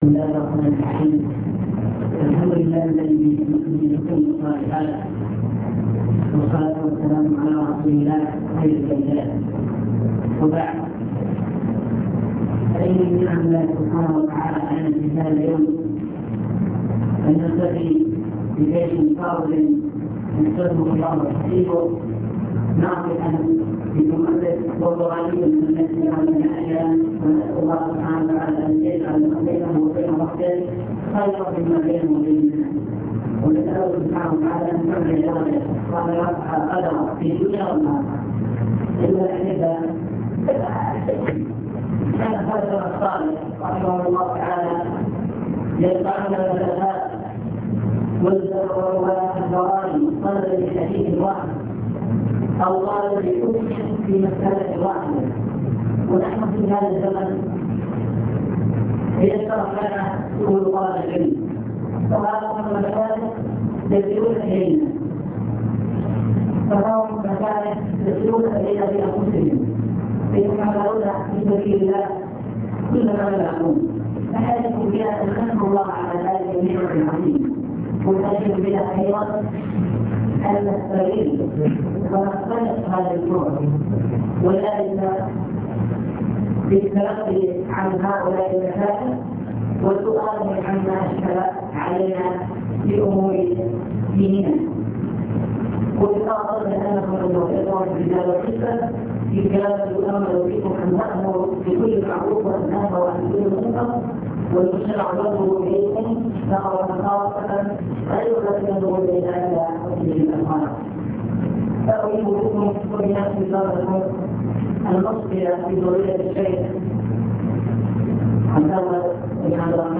المكتبة الصوتية  تسجيلات - لقاءات  كلمة في رئاسة الأمر بالمعروف الدعوة إلى الله تعالى